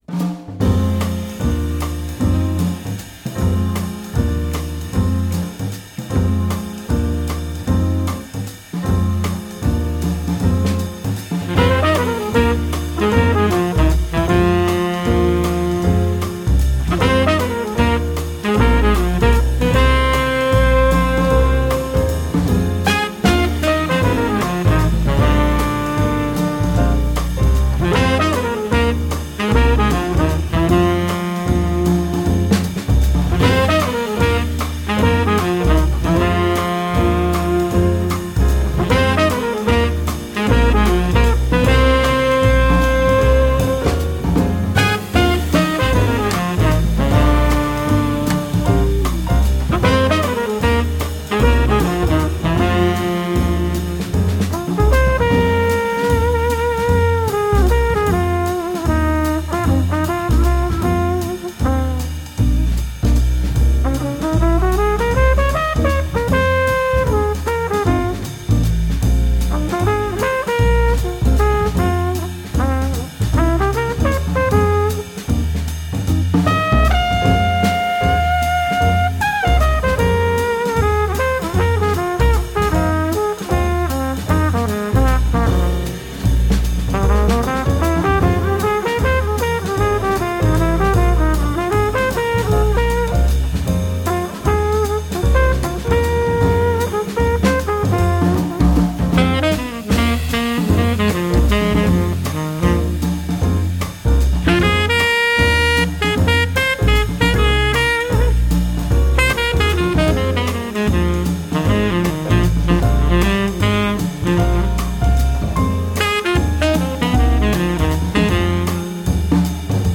trumpet
saxophone
guitar
piano
bass
drums